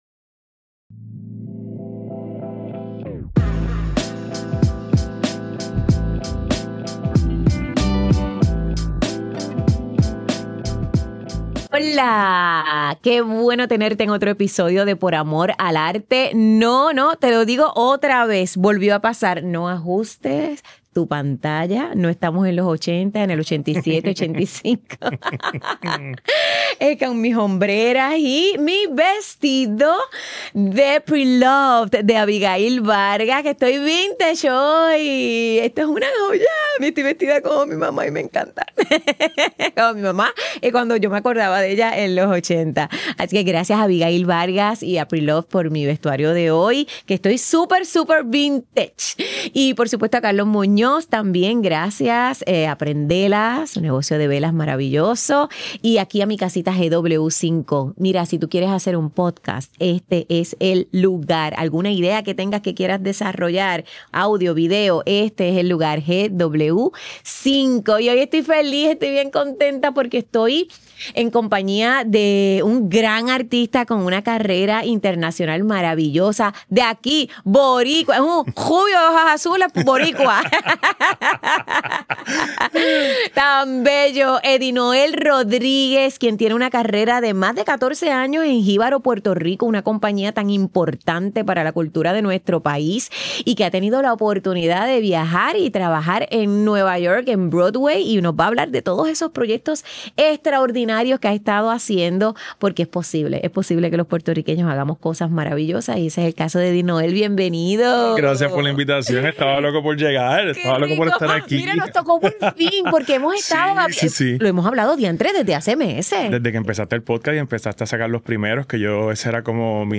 Grabado en GW-Cinco Studio para GW5 Network